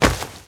SFX_saltoSacos4.wav